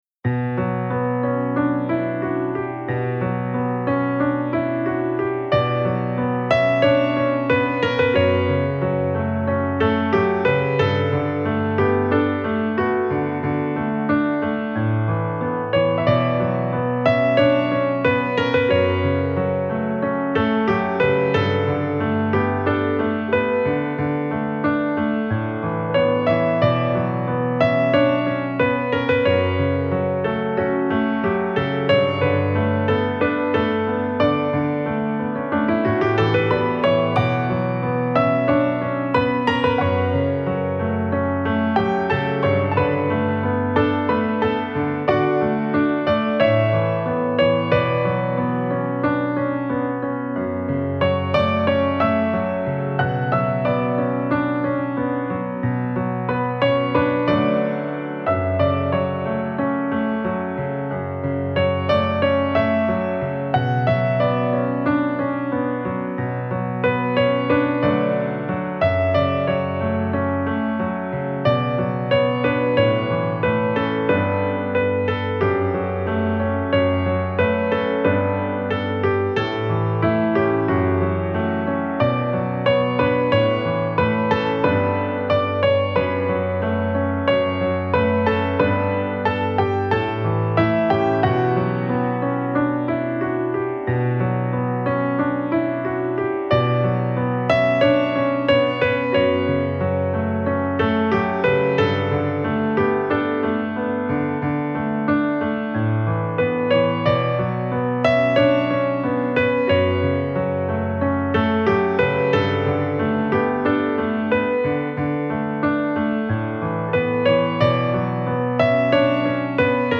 سبک آرامش بخش , پیانو , عاشقانه , موسیقی بی کلام